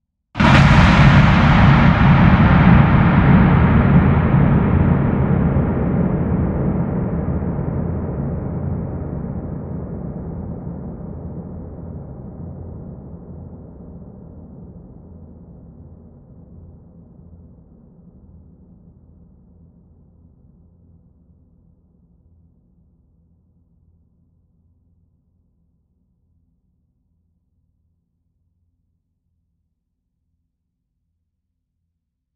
На этой странице собраны звуки, которые ассоциируются с концом света: от далеких взрывов и гула сирен до хаотичного шума разрушающегося мира.
Последний космический взрыв в Солнечной системе